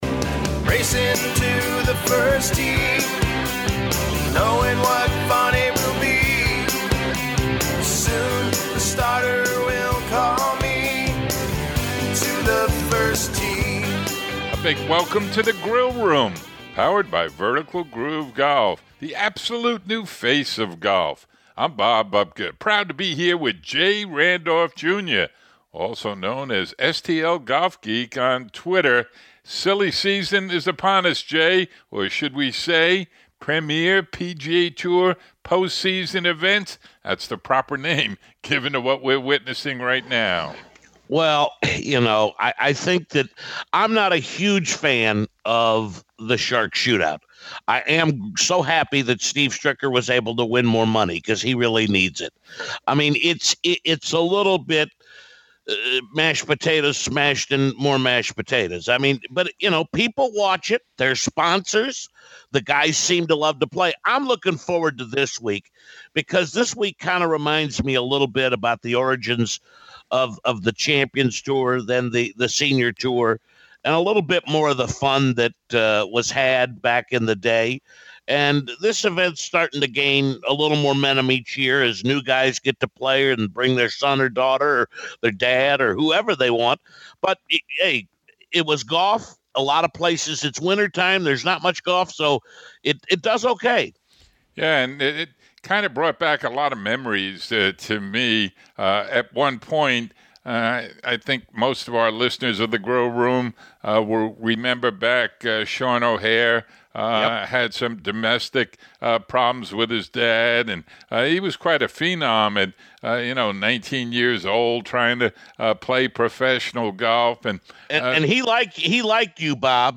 Both Sean and Steve talk share their thoughts.